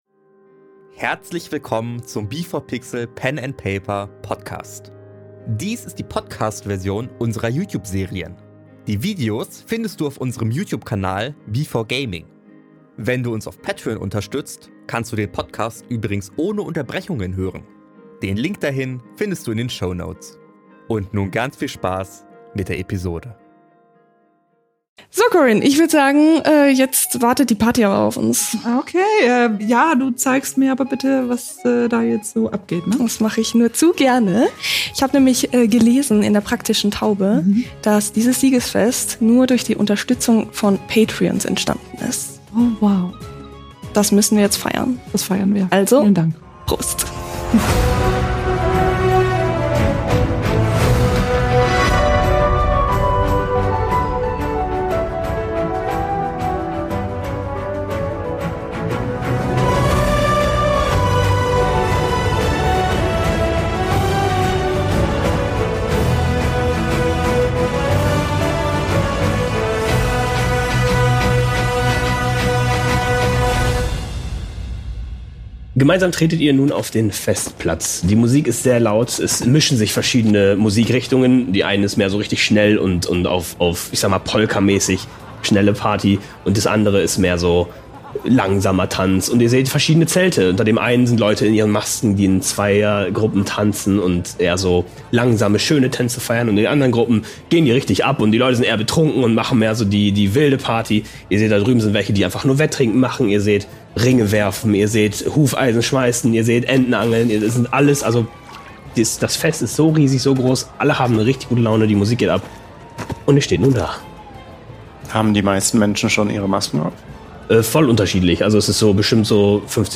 Wir spielen auf dem YouTube B4Gaming regelmäßig Pen and Paper und veröffentlichen dort unsere Serien. Dies hier ist die Podcast-Version mit Unterbrechungen.